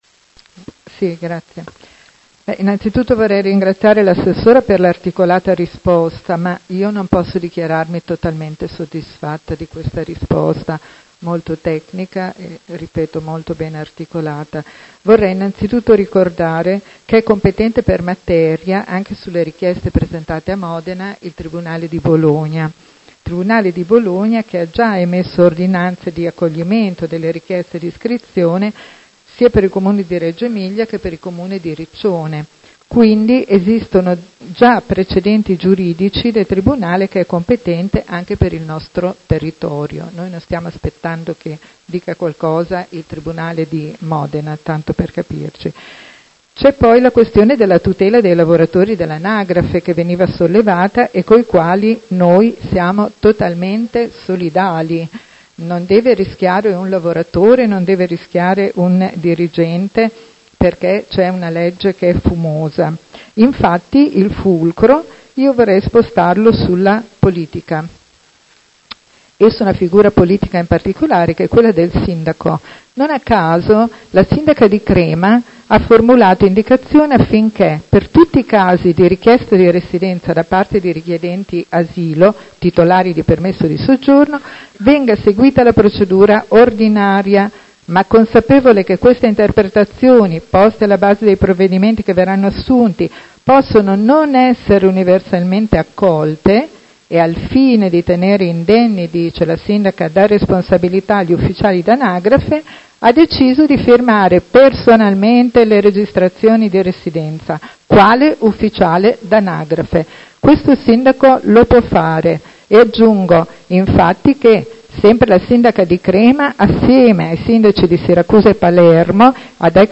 Paola Aime — Sito Audio Consiglio Comunale
Seduta del 17/10/2019 Replica a risposta Assessora Ludovica Ferrari. Interrogazione Prot. Gen. n. 245236 della Consigliera Aime (Verdi) avente per oggetto: Il Comune di Modena intende iscrivere all’anagrafe i cittadini stranieri richiedenti asilo?